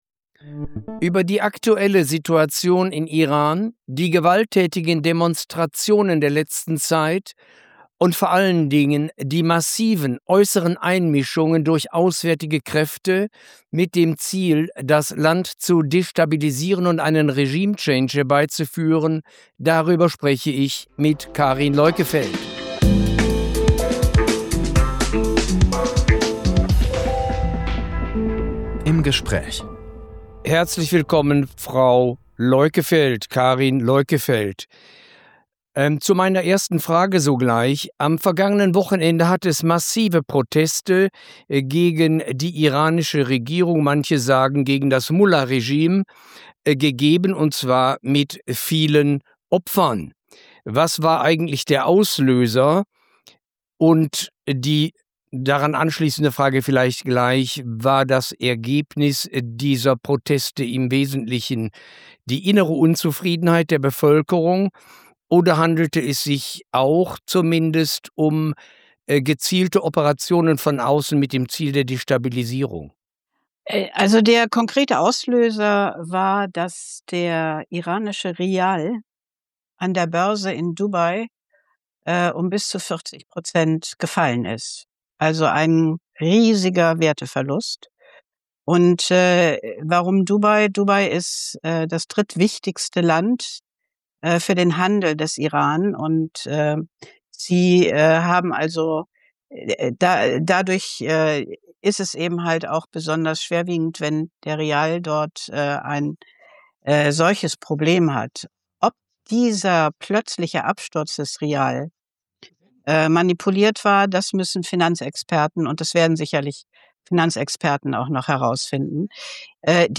Im Gespräch Aktualisiert am Jan. 18, 2026